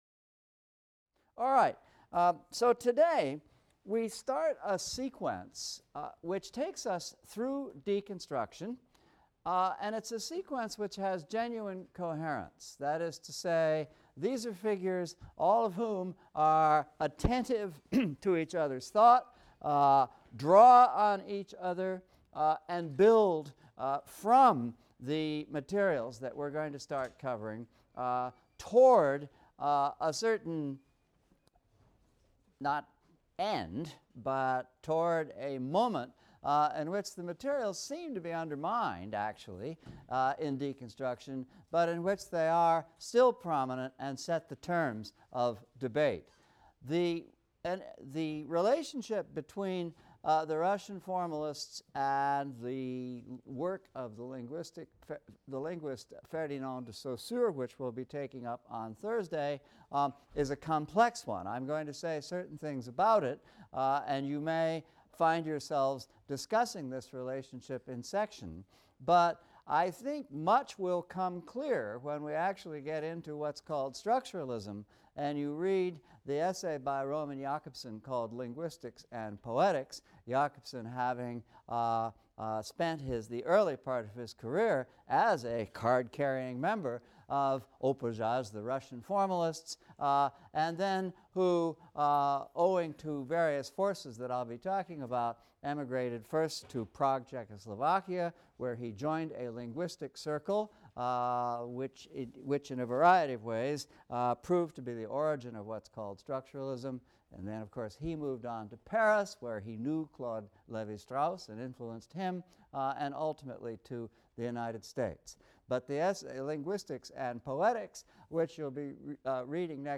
ENGL 300 - Lecture 7 - Russian Formalism | Open Yale Courses